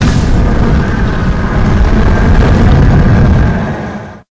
flamethrower_shot_07.wav